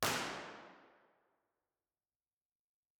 impulseresponseheslingtonchurch-006.wav